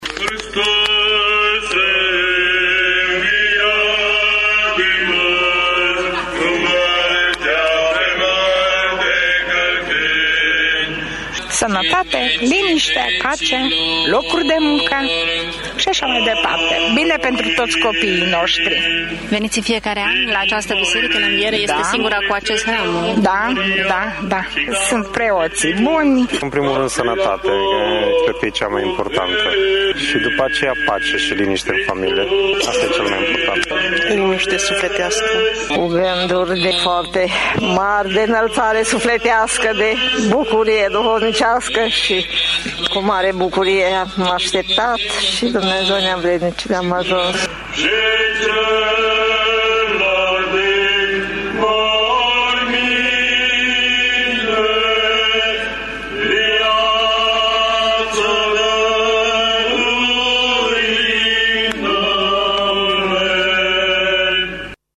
În toate bisericile ortodoxe și greco-catolice din oraş a fost săvârşită slujba de Înviere prin care s-a celebrat biruinţa vieţii asupra morţii.
La fel s-a întâmplat și la singura biserica ortodoxă din Tîrgu-Mureș care poartă hramul ”Învierii Domnului”.